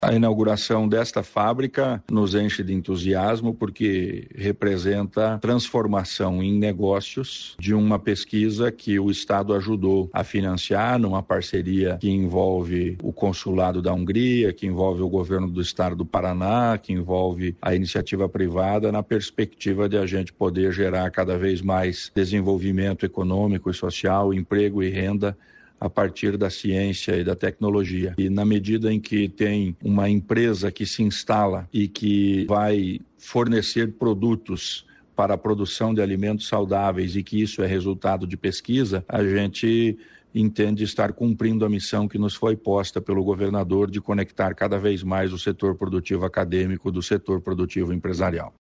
Sonora do secretário estadual da Ciência, Tecnologia e Ensino Superior, Aldo Nelson Bona, sobre tecnologia húngara que protege plantas de fungos e bactérias